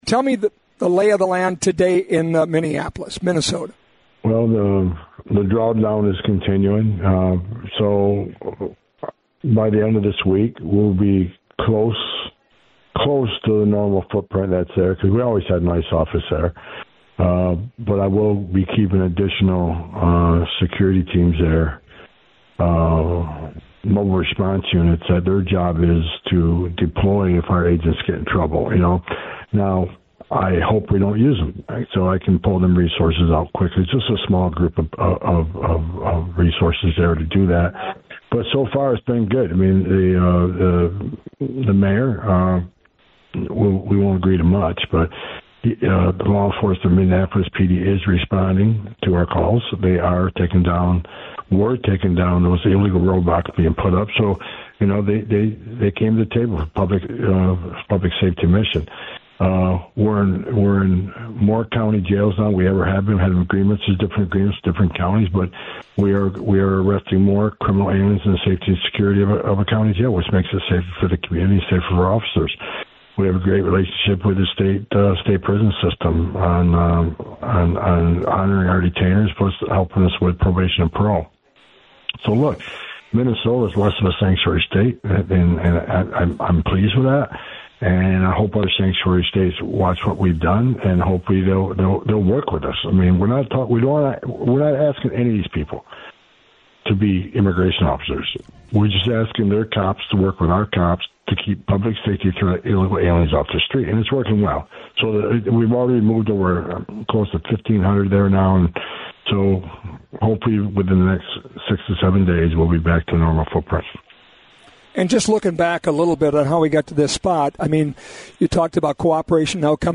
MINNESOTA – In an exclusive interview on The Flag, White House Border Czar Tom Homan said the amount of Immigration and Customs Enforcement agents in Minneapolis and in Greater Minnesota should be at the level they were before Operation Metro Surge began by the end of this week.